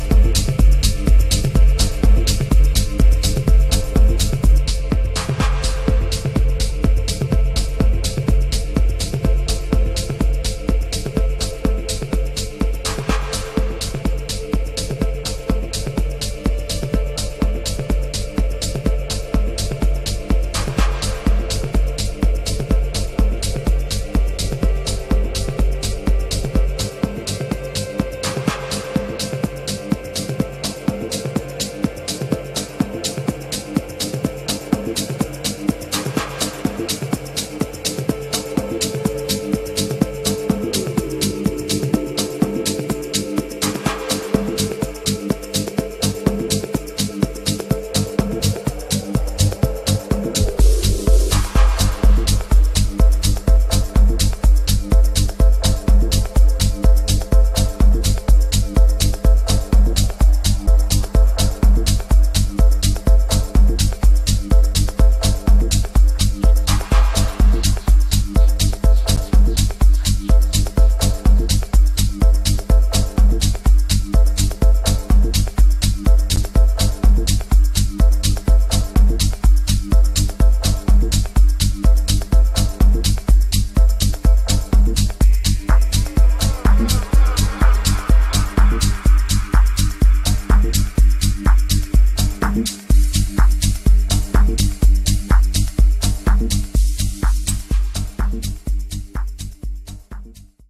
Mescla musical